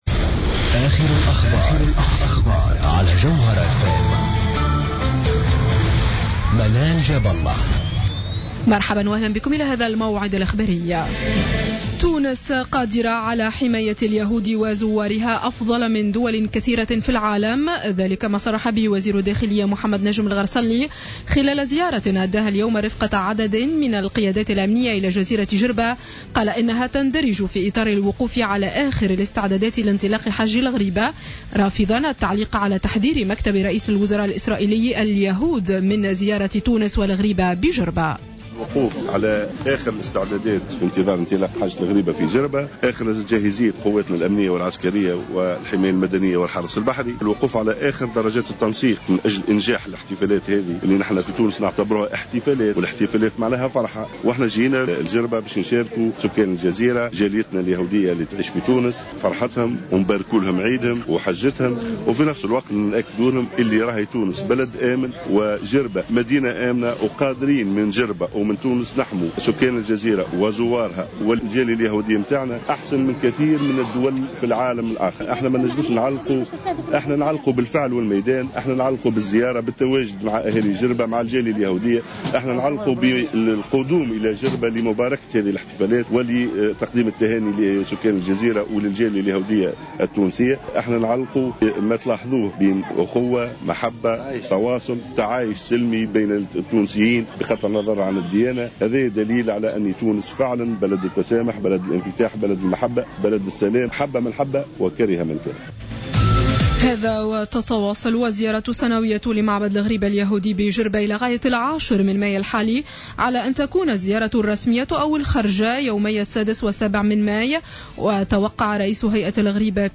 نشرة أخبار السابعة مساء ليوم الأحد 03 ماي 2015